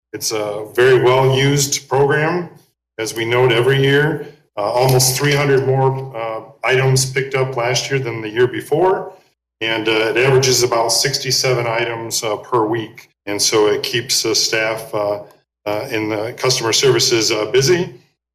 City Manager Keith Baker told the Coldwater City Council on Monday night there were 3,509 large items picked up at the curb by Republic in 2022 which was 291 more than in 2021.